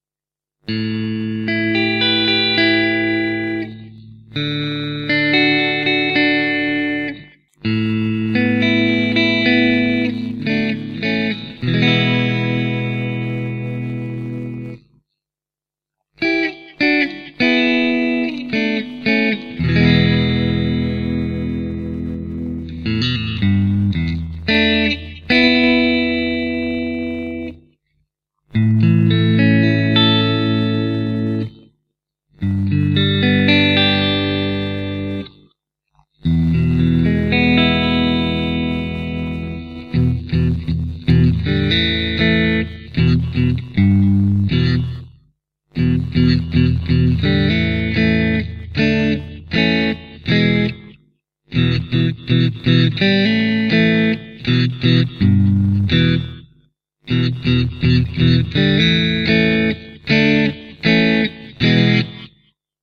It has Alnico 5 rods and vintage windings but with the bridge pickup noticeably hotter and the neck pickup a touch softer.
It offers a spankly top end with warm but tight bass and open, airy mid range. Exactly what you'd expect from a vintage Jazzmaster pickup.
Listen here:     Bridge